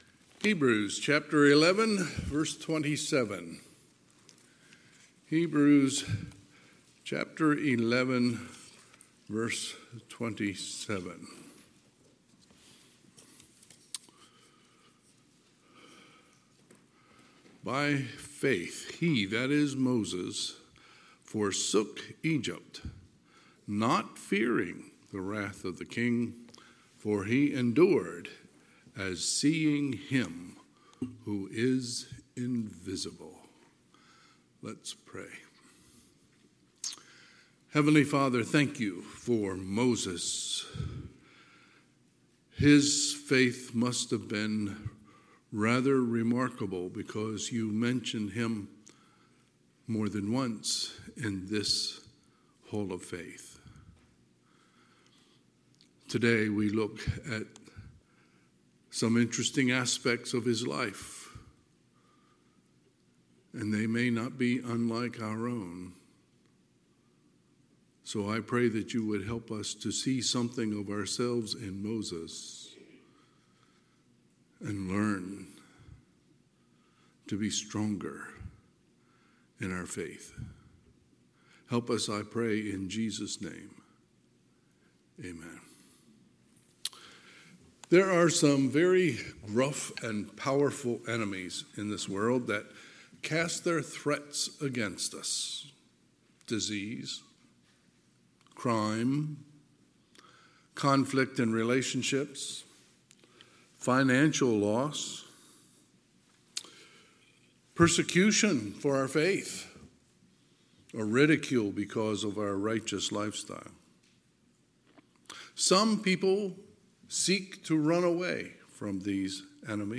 Sunday, October 20, 2024 – Sunday AM
Sermons